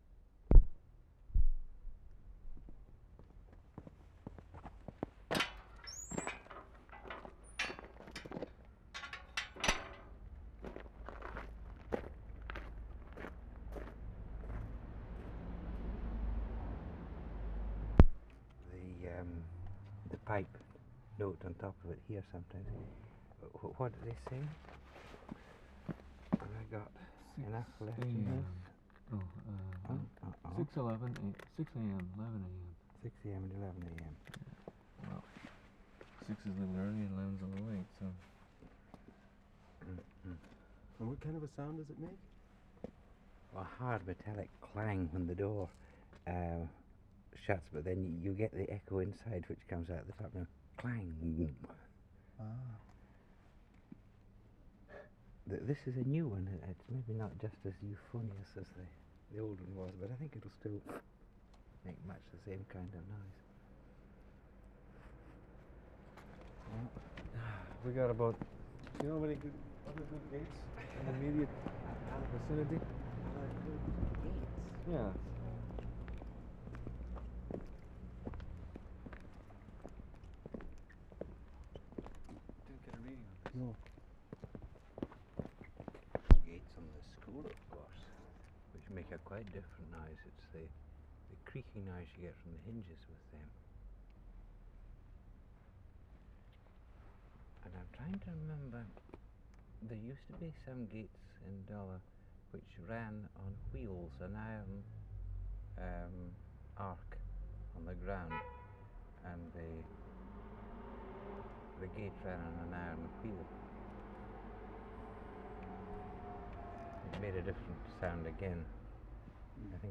WORLD SOUNDSCAPE PROJECT TAPE LIBRARY
OPENING VARIOUS GATES in Dollar.